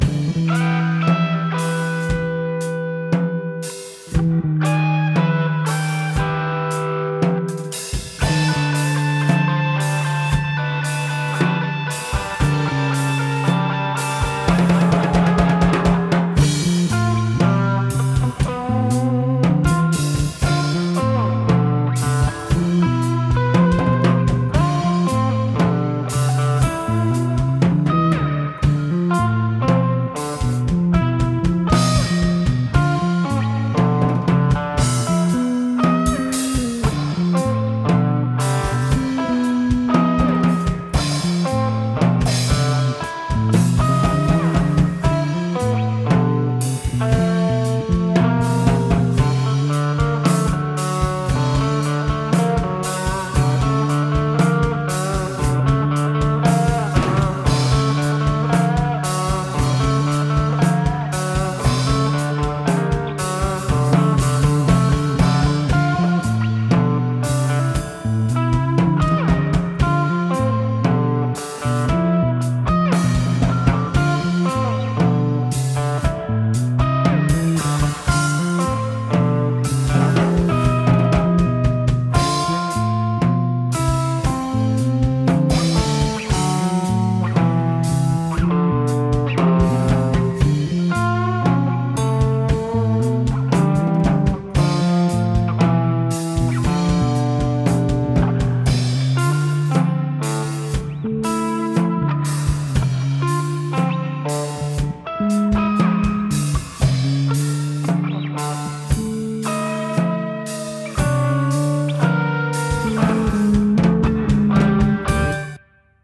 En esta sección GRAVELAND encontraréis periódicamente grabaciones en directo, en baja calidad (grabados en cassete con un micro colgado de un palo), con comentarios banales pisando inicios y finales de algunos cortes, discusiones sobre paridas insólitas, batallas de volúmenes, y bromas y referencias personales que a veces ni siquiera entendemos nosotros al hacer la reaudición.